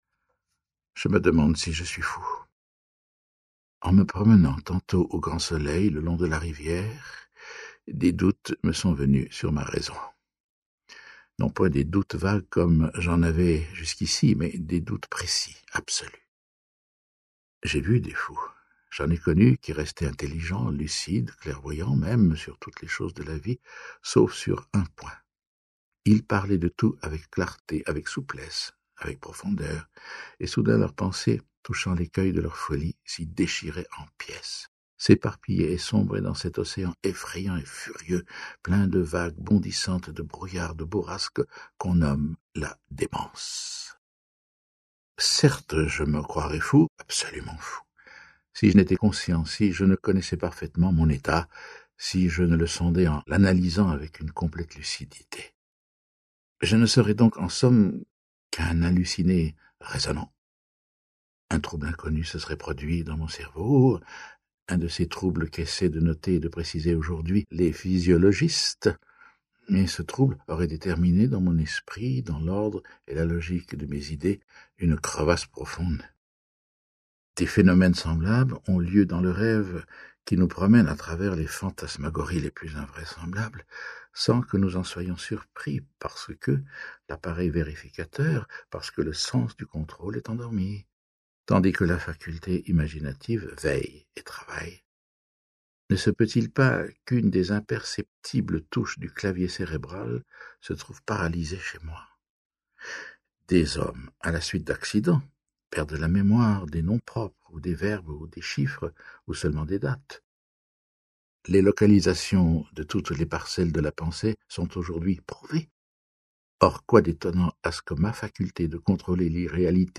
Diffusion distribution ebook et livre audio - Catalogue livres numériques
Grand lecteur, il prête sa voix à des textes littéraires et philosophiques à la scène et en livres audio. 8 , 10 € Ce livre est accessible aux handicaps Voir les informations d'accessibilité